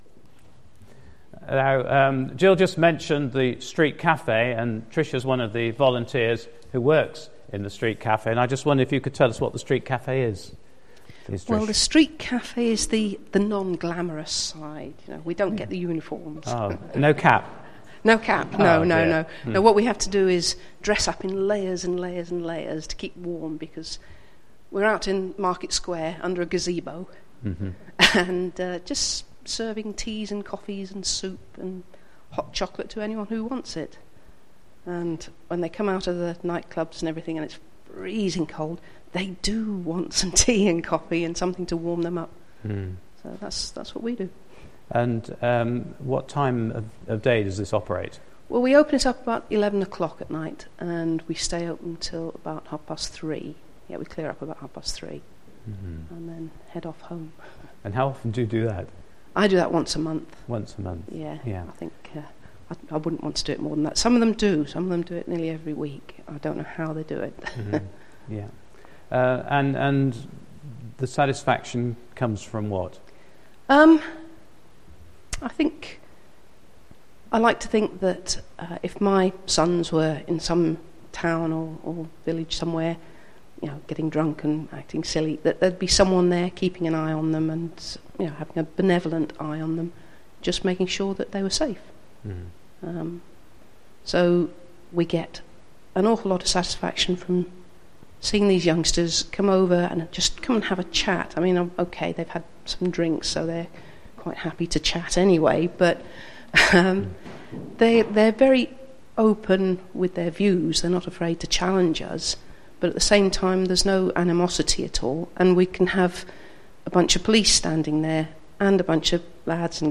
Today’s service celebrates Back to Church Sunday and there are four talks.